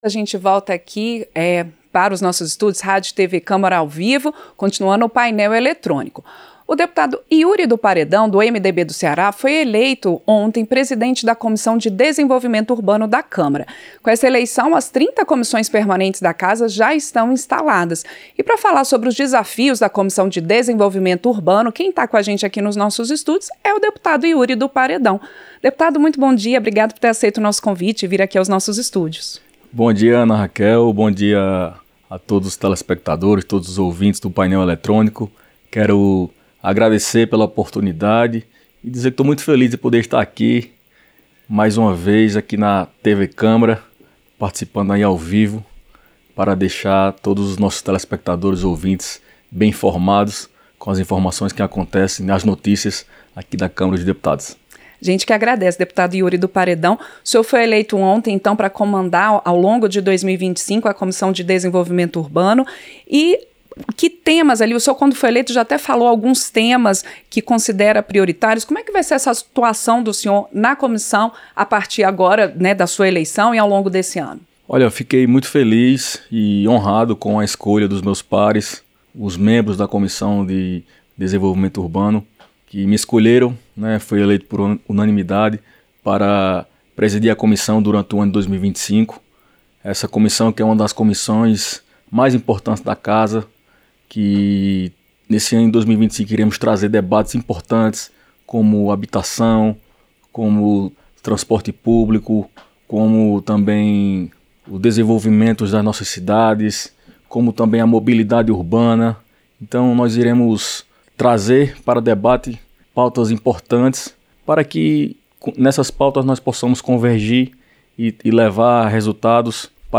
Entrevista - Dep. Yury do Paredão (MDB-CE)